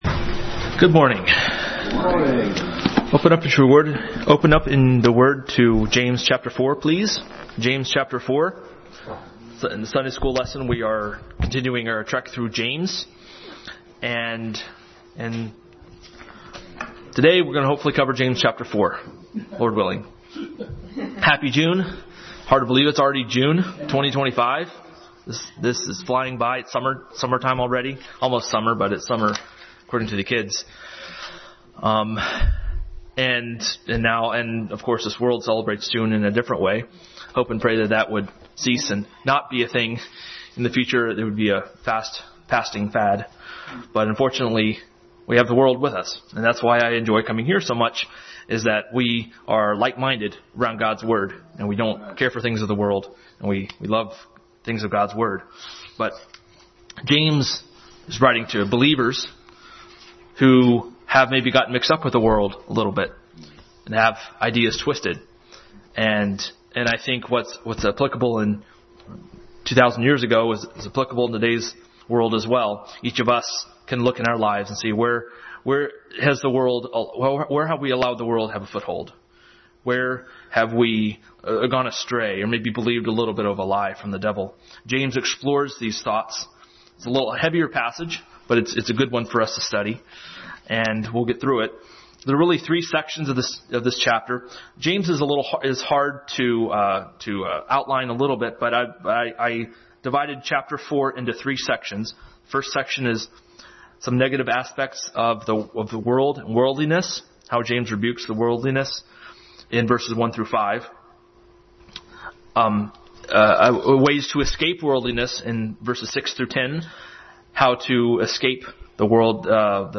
James 4:1-17 Passage: James 4:1-17, Romans 8:7, 5:10 Service Type: Sunday School